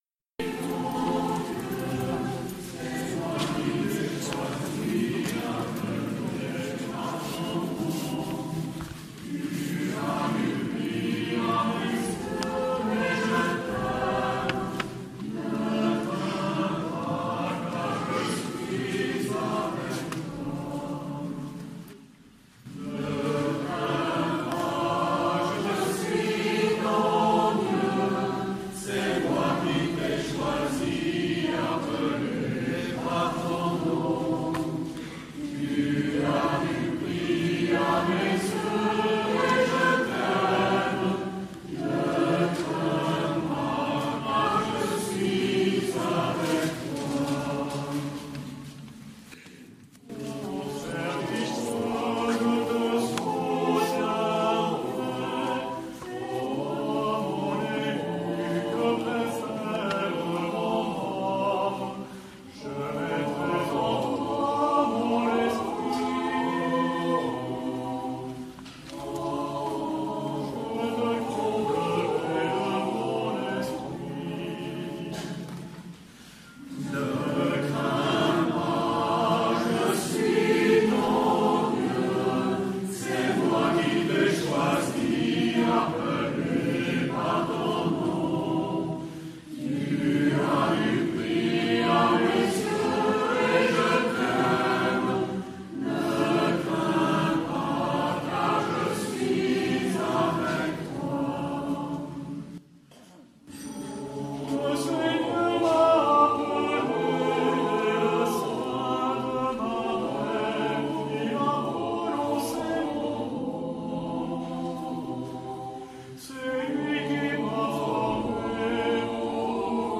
Fête des baptisés 2017
♦ Chant d’entrée :